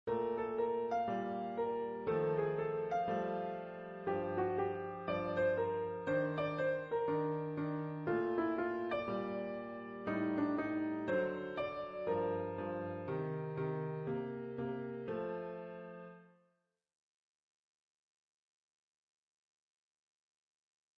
Jazz/Improvisierte Musik
Sololiteratur
Klavier (1)
Komposition in Jazzstandardform. Thema-Solo-Reprise.